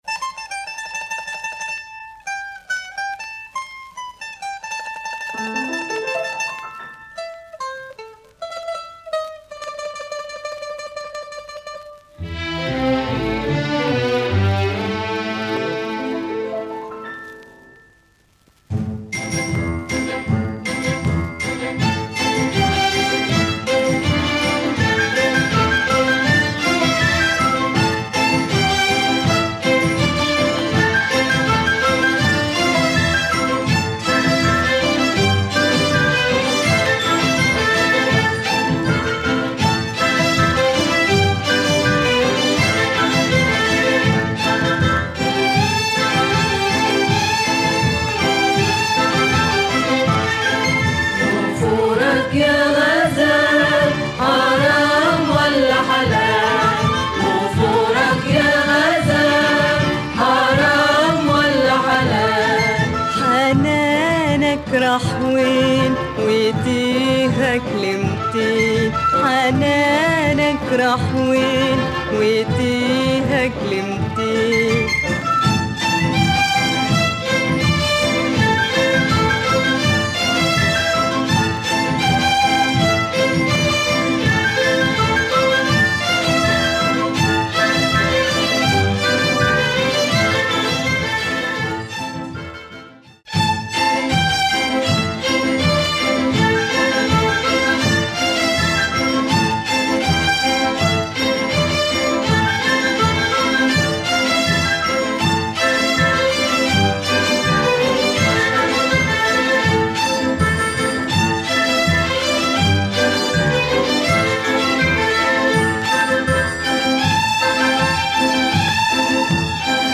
Nubian female singer